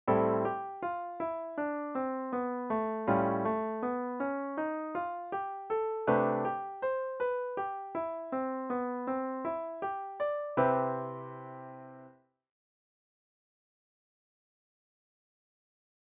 Just a recent discovery for a 2 / 5 / 1 lick with the whole tone color. Thinking 'C' major; run the 'A' natural minor's pitches, (relative to C major) over the 'D' min 9 chord ( ii-9), then morph the 'A' natural minor into 'A' wholetone group of pitches / scale :) Please examine the chart of the three scale's letter names and hear a basic 'morph' riff of these colors towards a resolution to 'C' major.